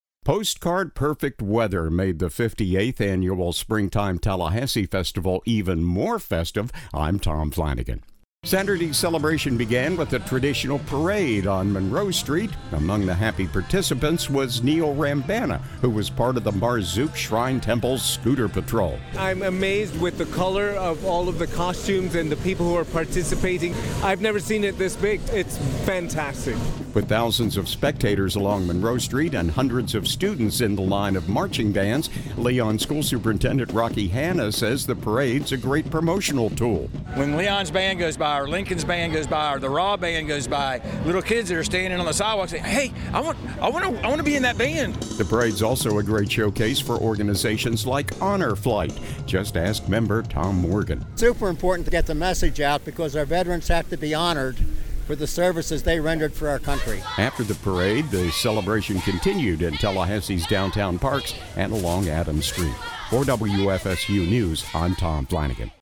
Saturday’s celebration began with the traditional parade on Monroe Street.